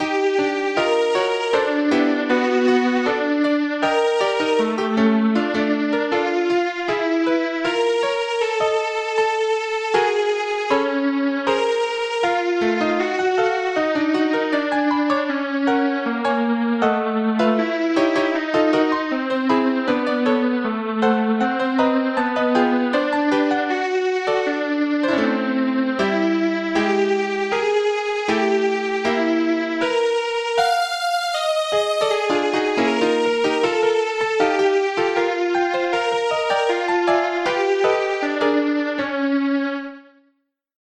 vaporwave, chiptune, videogame music, vgm, midiwave,